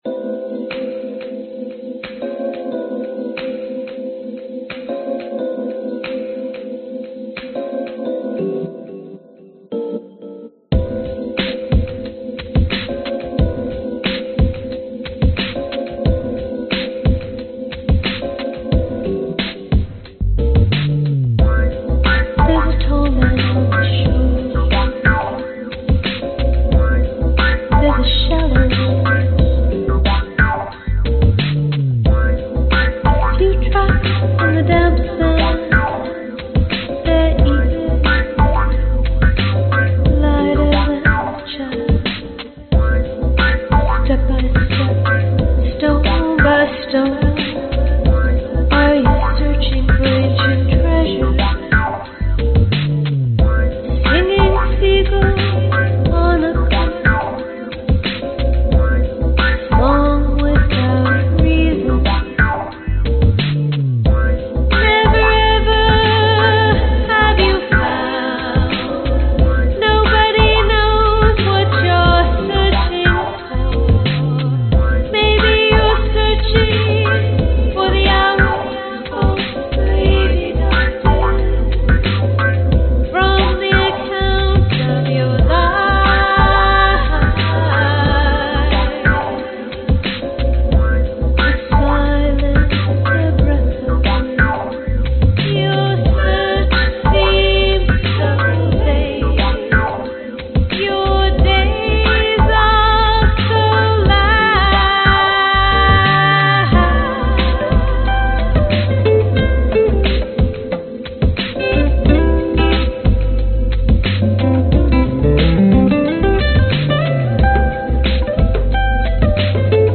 标签： 贝斯 冷酷 电子 电子 吉他 合成器
声道立体声